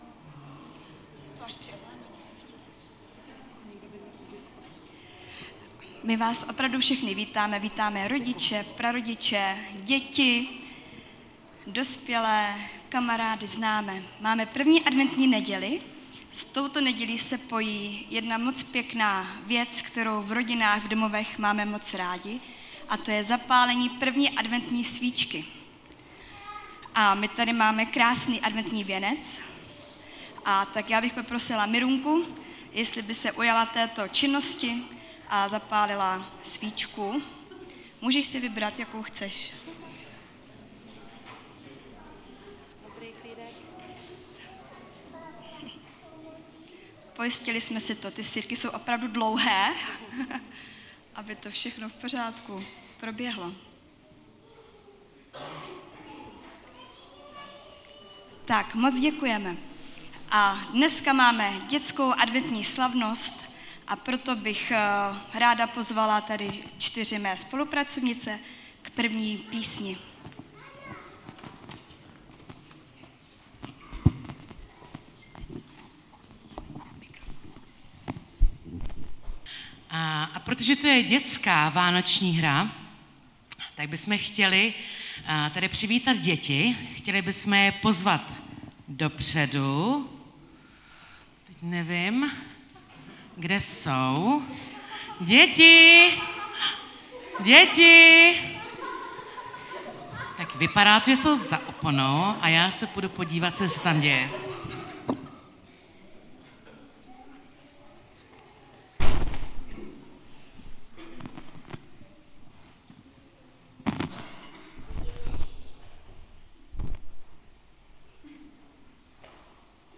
Vánoční slavnost s vystoupením dětí